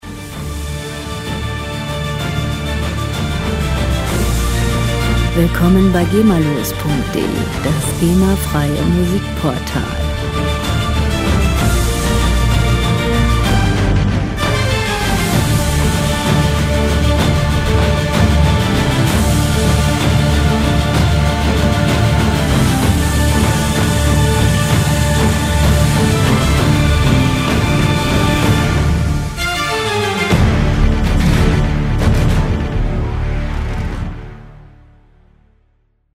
Filmmusik - Abenteuer
Musikstil: Soundtrack
Tempo: 63 bpm
Tonart: D-Moll
Charakter: monumental, kraftvoll
Instrumentierung: Orchester